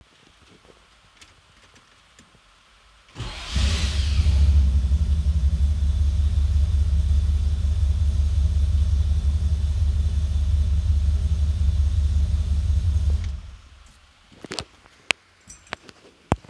Took some quick recordings of the XS Torque starter.
The second is it starting after a single pump. You can hear the same whirring noise right after it fires.
These are both taken inside the cab with everything closed up.
Yes, this is inside a metal shop building that even if it is misting outside it sounds like a downpour.
XSTorqueFord460-C6.wav